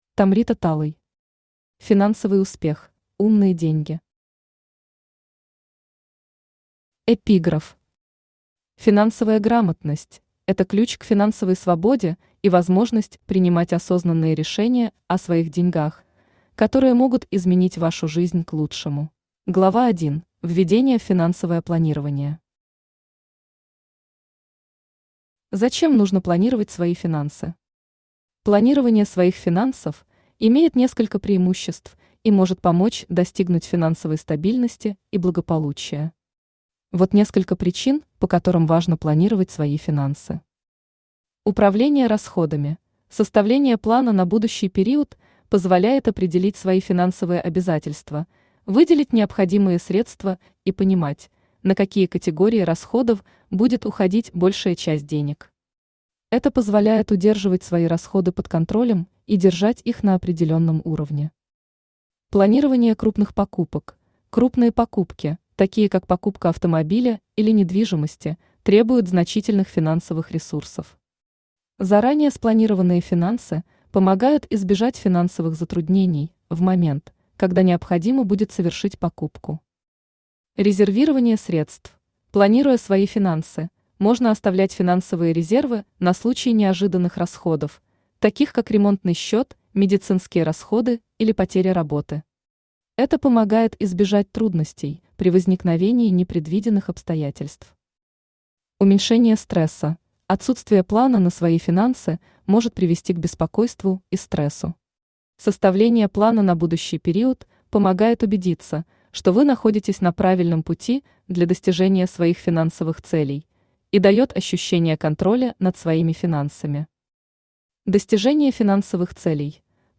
Aудиокнига Финансовый успех: Умные деньги Автор Tomrita Talay Читает аудиокнигу Авточтец ЛитРес.